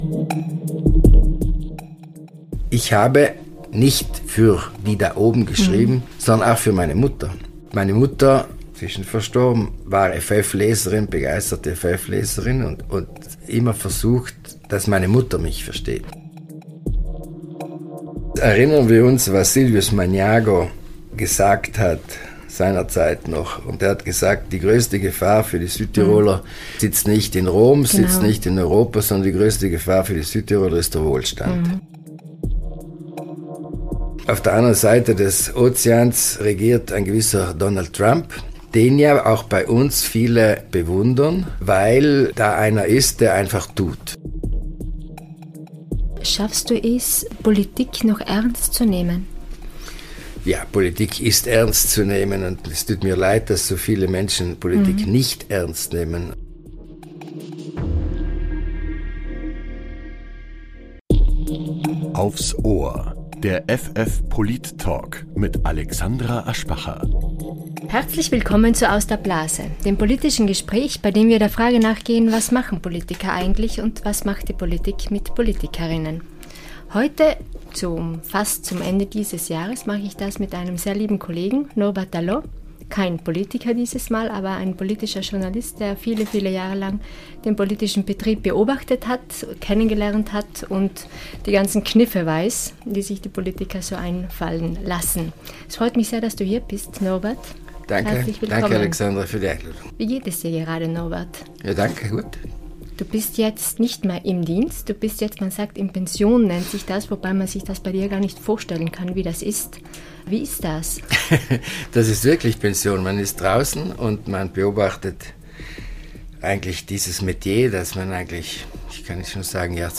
Der Politik-Talk